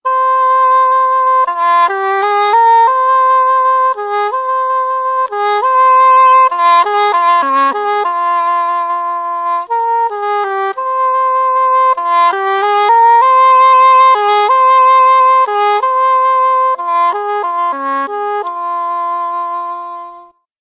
Hautbois.mp3